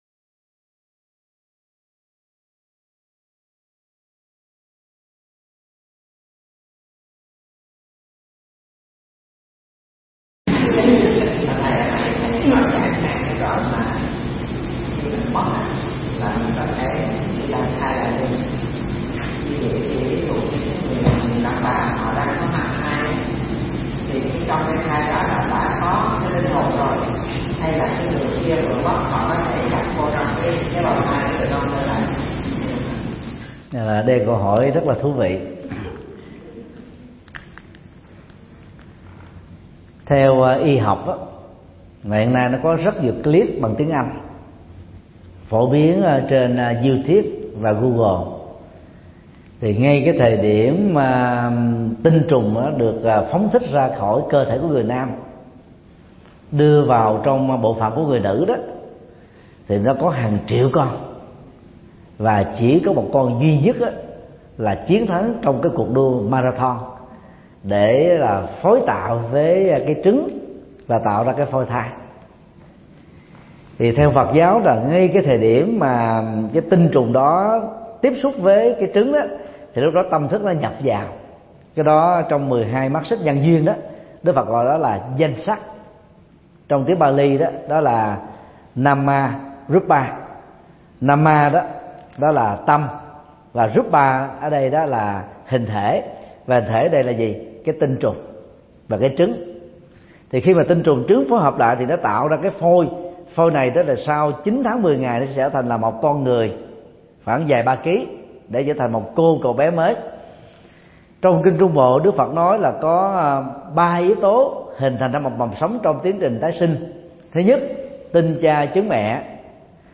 Mp3 Vấn đáp: Danh sắc trong 12 nhân duyên – Thượng Tọa Thích Nhật Từ giảng tại chùa Liên Hoa, Hoa Kỳ, ngày 14 tháng 6 năm 2017